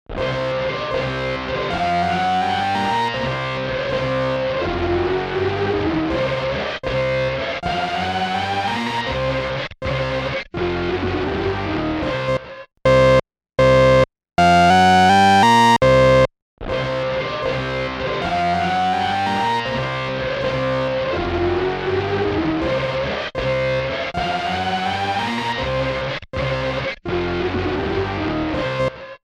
豊潤なリバーブと、引き裂くようなディストーション
MangledVerb | Synth | Preset: Power Chord It
MangledVerb-Synth-Preset-Power-Chord-It.mp3